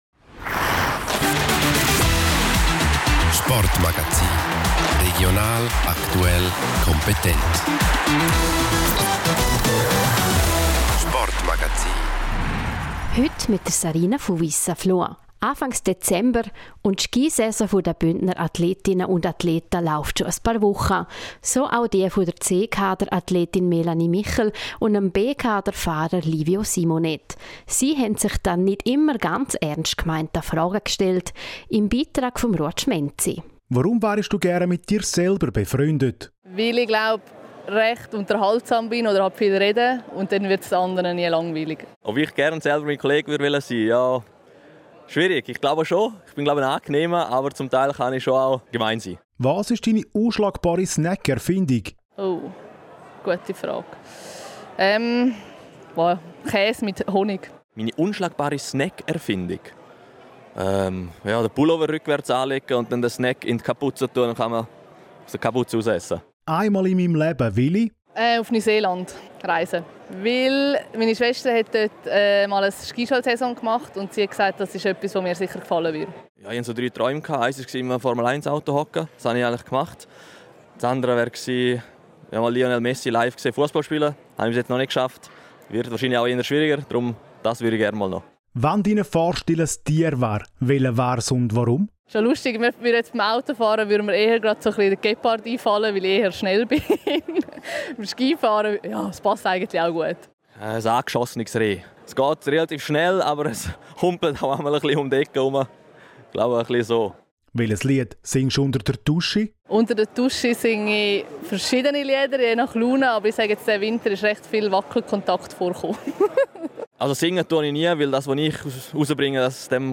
Lustiges Interview Teil 2: Bündner Ski-Cracks beantworten die etwas anderen Fragen